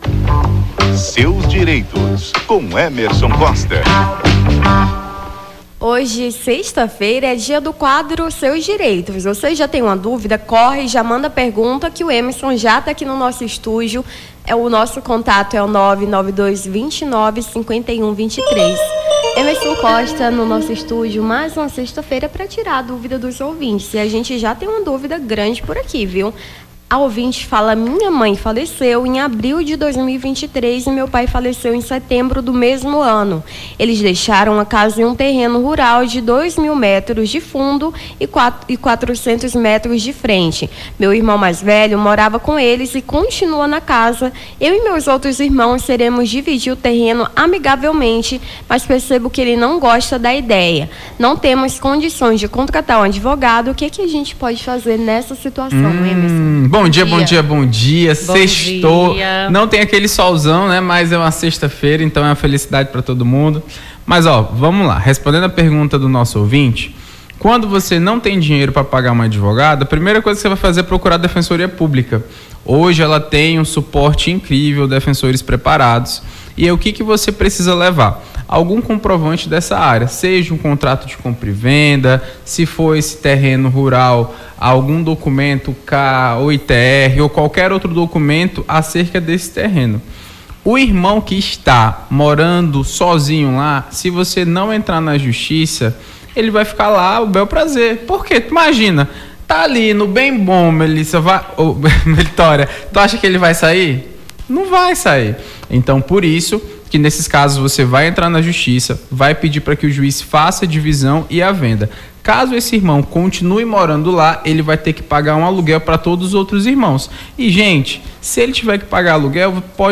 Seus Direitos: advogado esclarece dúvidas dos ouvintes sobre direito de família